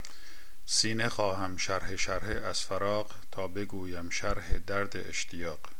Recite